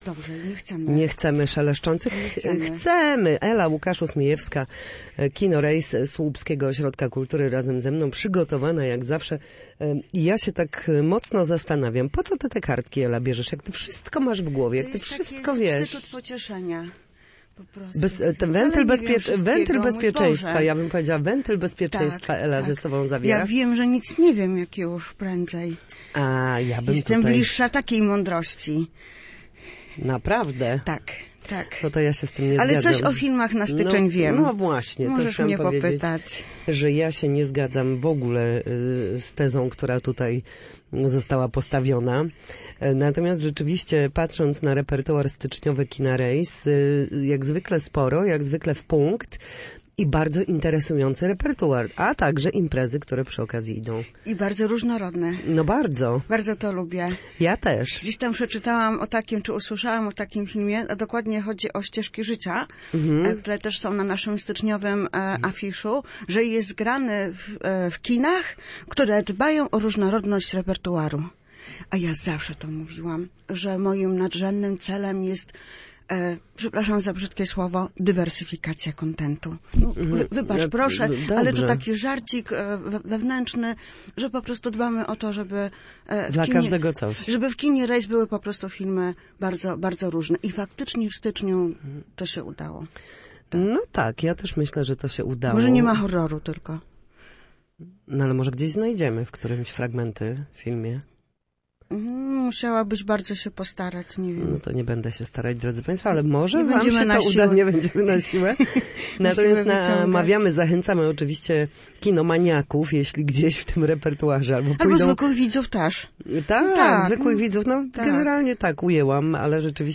Na naszej antenie zapraszała na styczniowe wydarzenia.